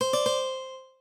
lute_cdc.ogg